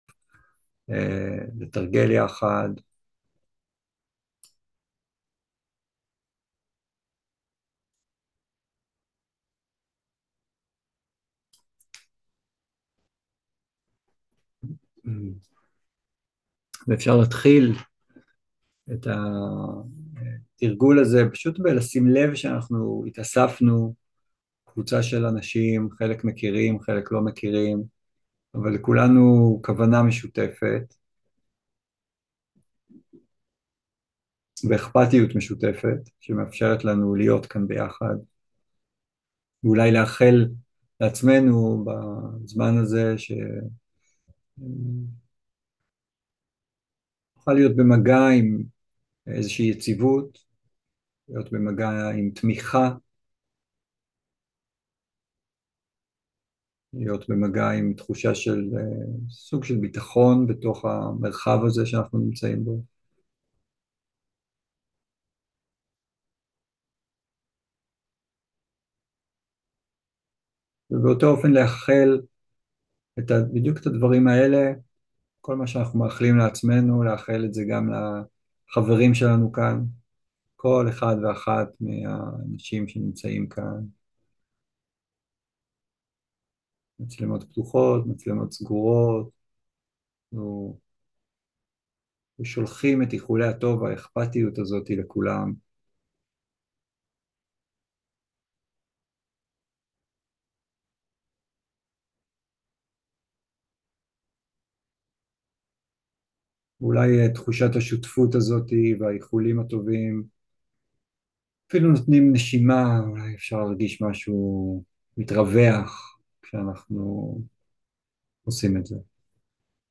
26.10.2023 - מרחב בטוח - יציבות ותמיכה - תרגול מונחה